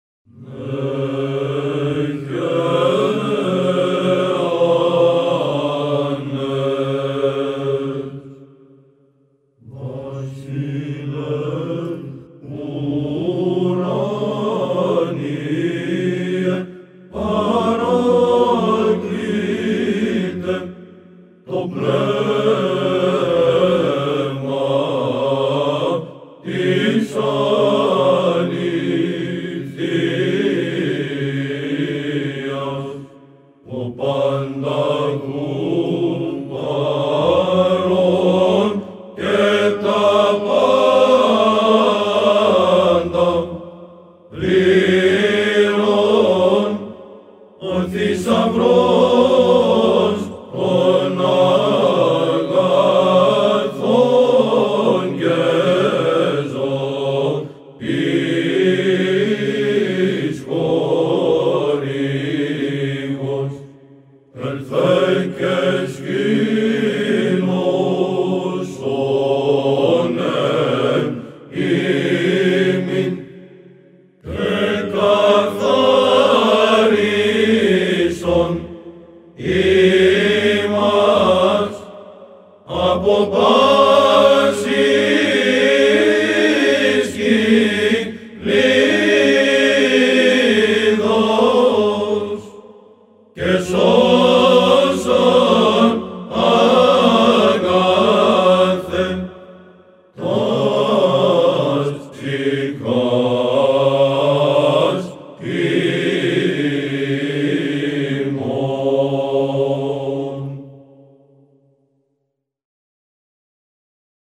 Православни песнопения на гръцки